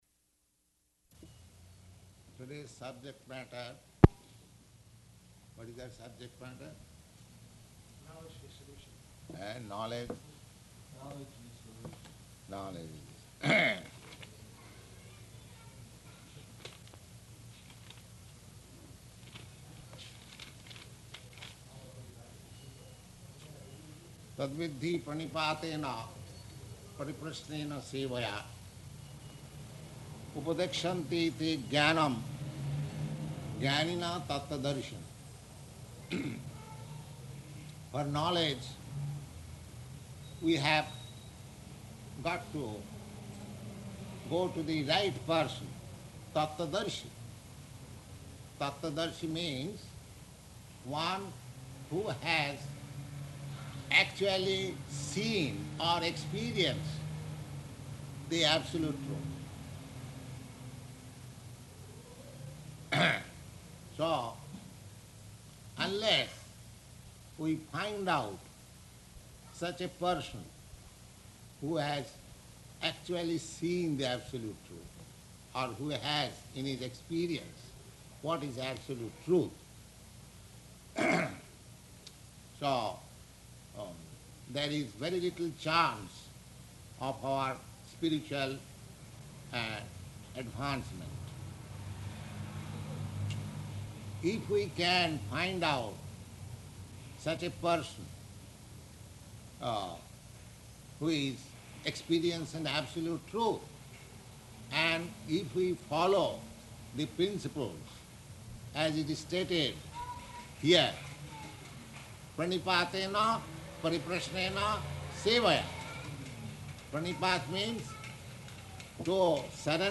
August 15th 1966 Location: New York Audio file